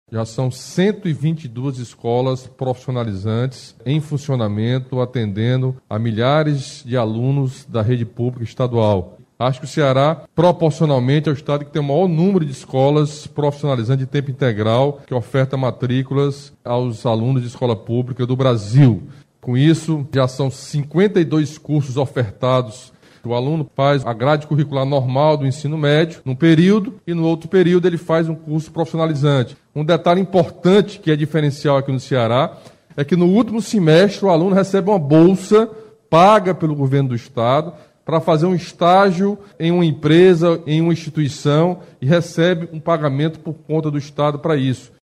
O governador Camilo Santana falou sobre o funcionamento da Escola de Alto Santo e destacou o avanço da educação profissionalizante no Estado.